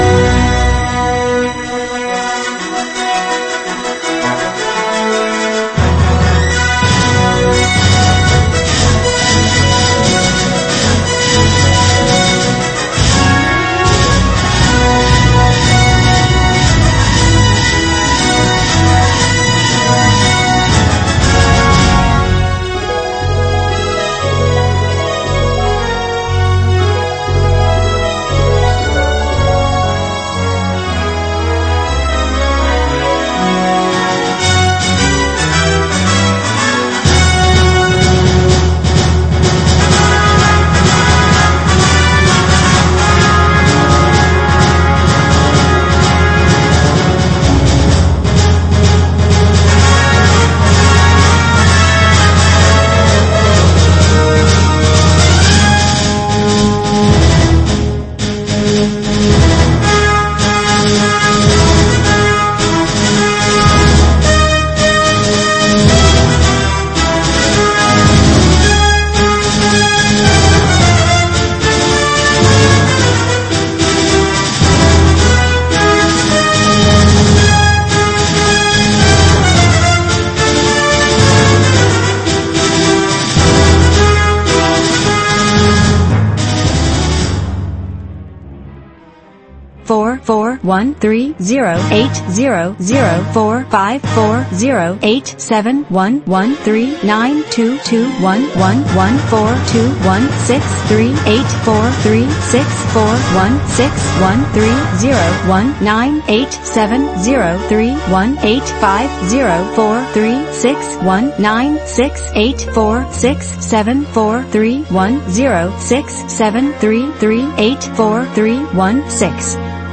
We can hear the synthesized voice of some digits starting from 1:29.